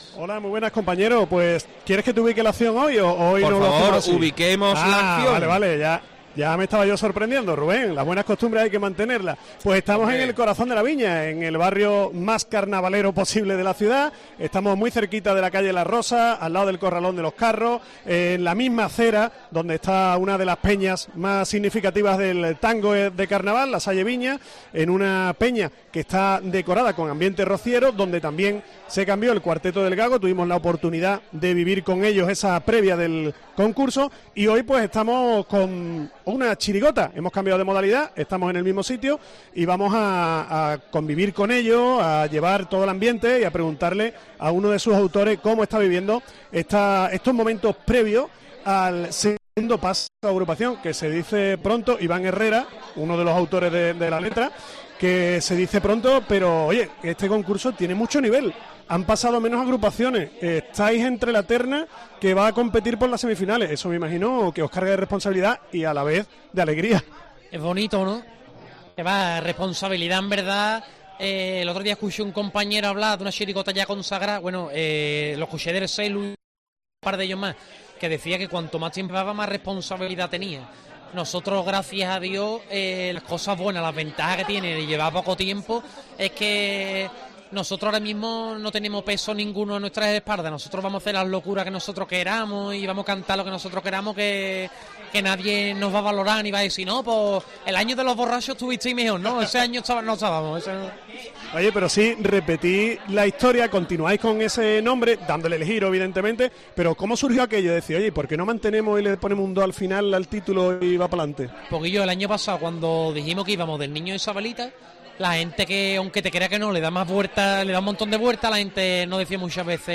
La previa de COPE con la chirigota 'El niño de Isabelita 2'